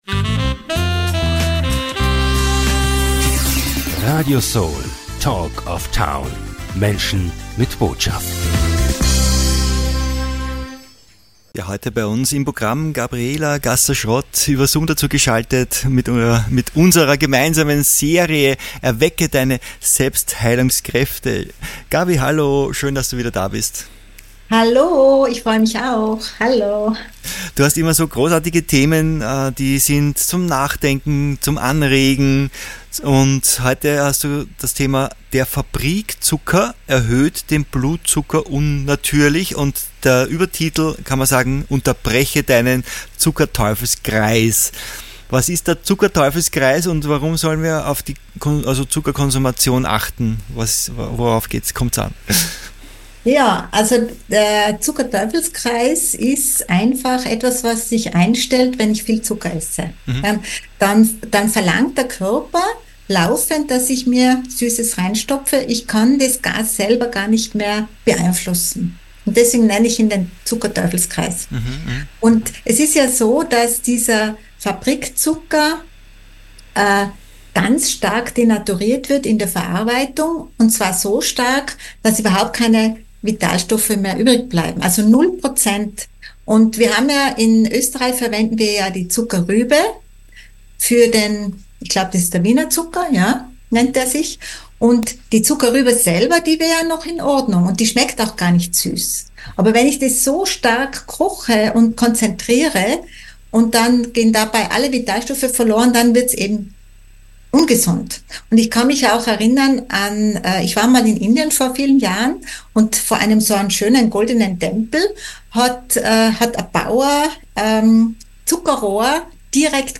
Der Fabrikzucker erhöht den Blutzucker unnatürlich. Alles über gesunde Alternativen erfährst du hier im Interview!